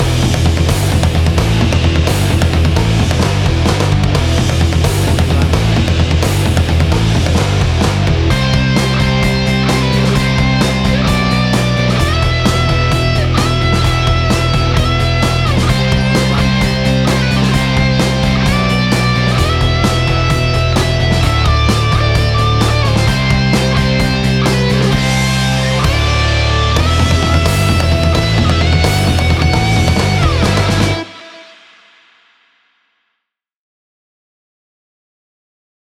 WAV Sample Rate: 16-Bit stereo, 44.1 kHz
Tempo (BPM): 130